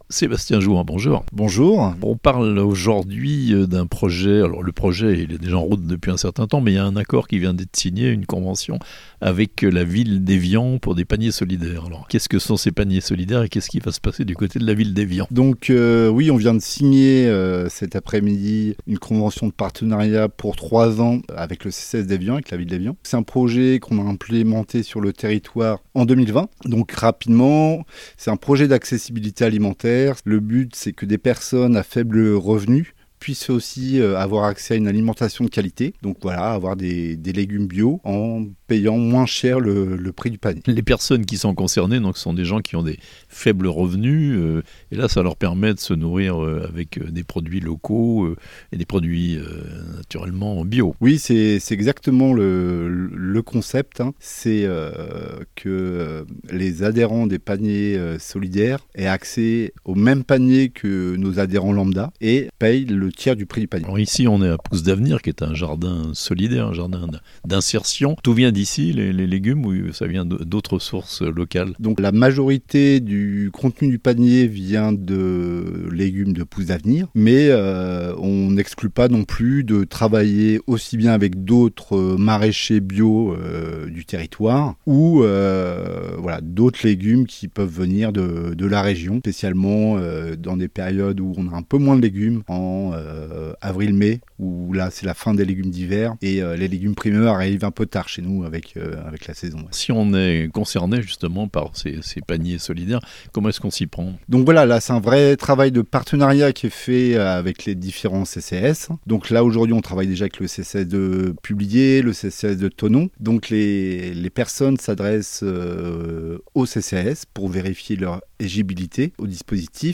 au micro La Radio Plus